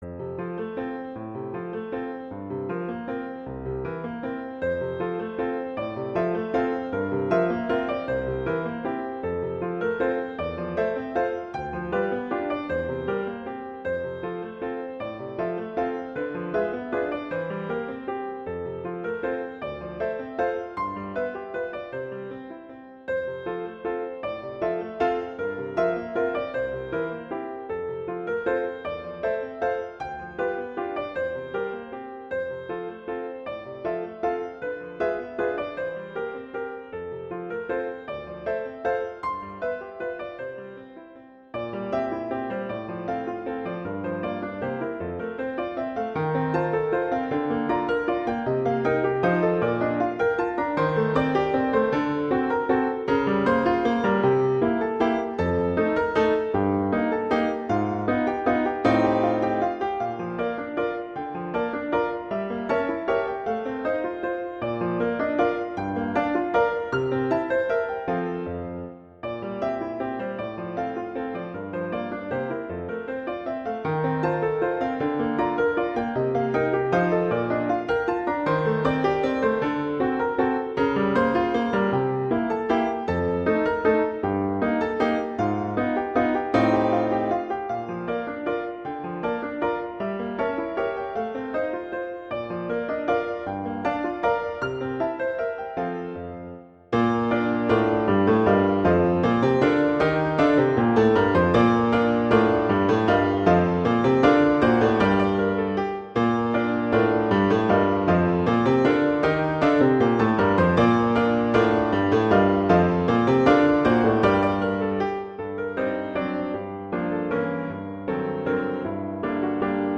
classical
F major
♩=156 BPM (real metronome 152 BPM)